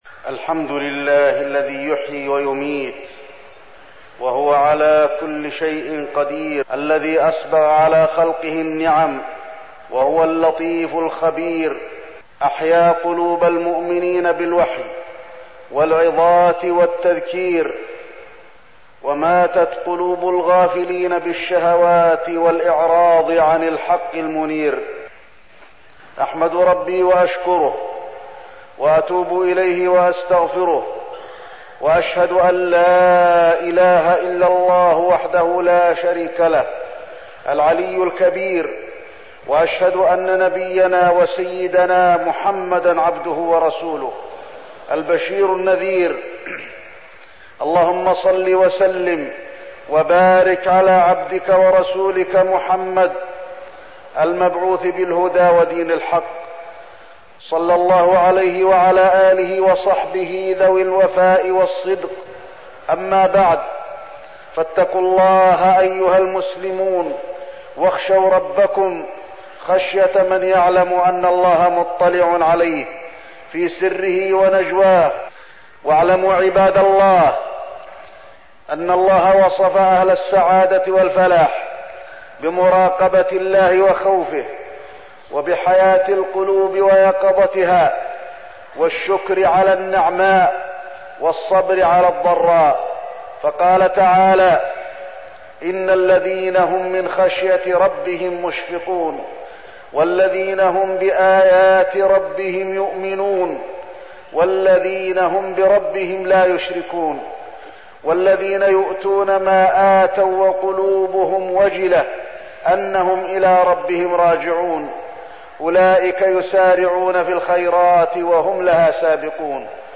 تاريخ النشر ٢٦ ربيع الثاني ١٤١٨ هـ المكان: المسجد النبوي الشيخ: فضيلة الشيخ د. علي بن عبدالرحمن الحذيفي فضيلة الشيخ د. علي بن عبدالرحمن الحذيفي الغفلة عن كتاب الله